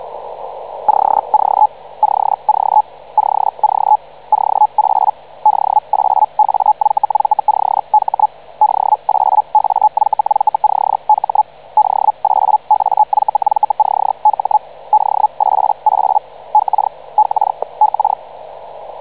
HELL / HELLSCREIBER
The Siemans systems used start/stop signalling (FSK) and the Field HELL unit was semi-synchronous. The system was used by the Chinese Internal Press up until about 1993 but is now used by European amateurs on 80m and 40m.